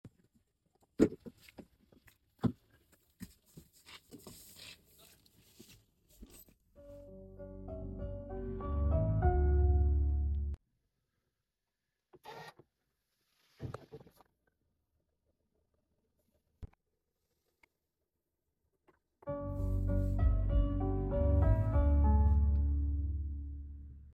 Korean traditional sound upon entering sound effects free download
Korean traditional sound upon entering the car & off engine.